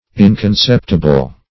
\In`con*cep"ti*ble\
inconceptible.mp3